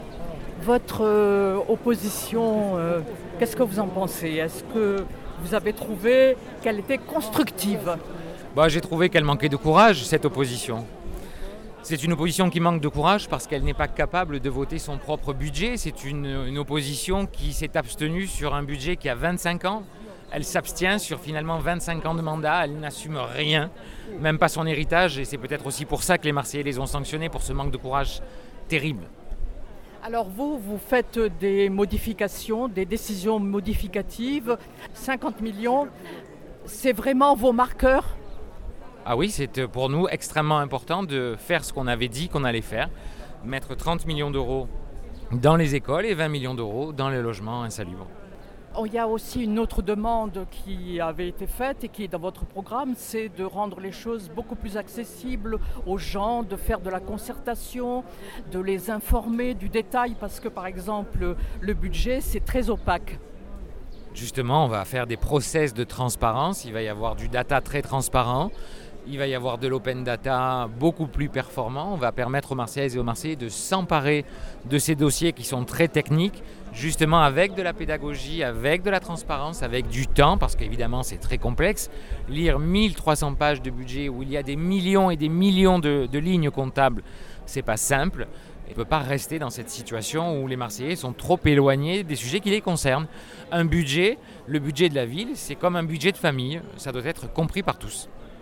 benoit_payan_lors_point_presse_matinee_27_07_20.mp3